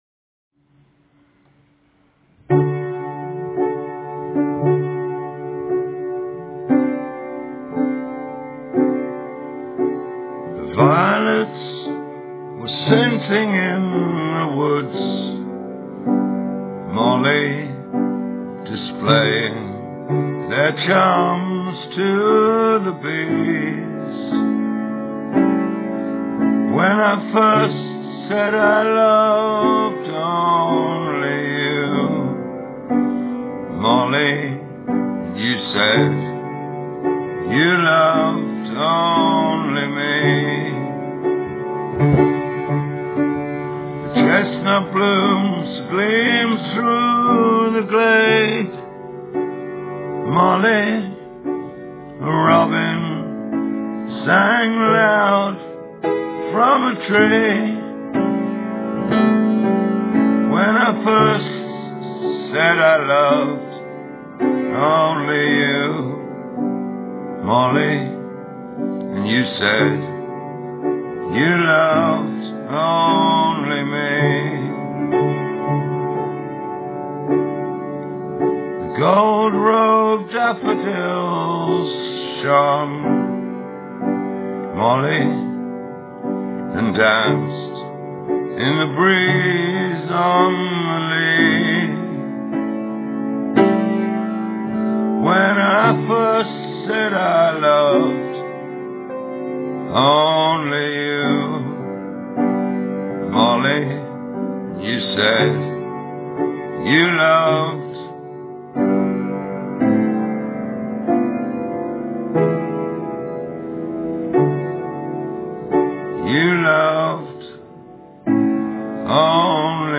Molly (Nora) - Irish Traditional